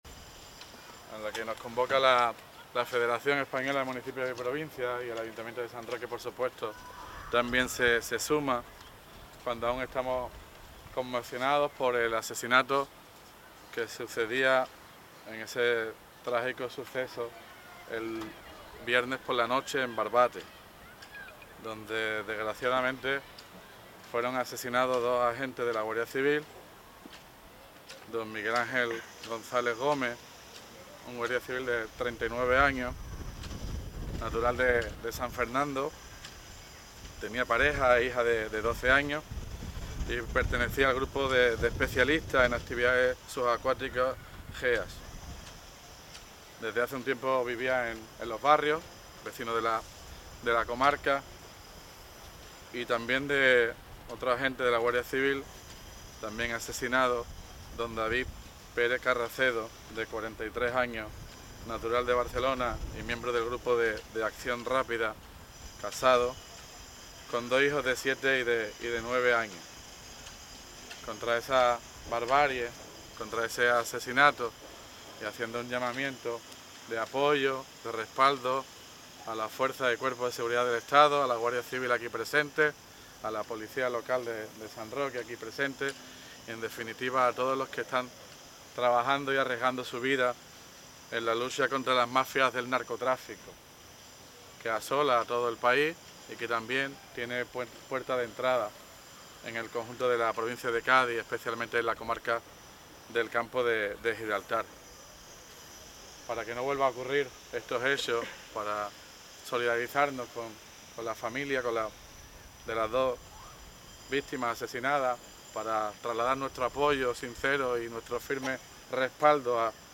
Numerosas personas han asistido en el mediodía de hoy, lunes, a la concentración que se ha realizado frente a la Casa Consistorial en repulsa por el asesinato de dos guardias civiles en Barbate, quienes fueron arrollados por una narcolancha.
TOTAL_ALCALDE_CONCENTRACIÓN_MINUTO_SILENCIO.mp3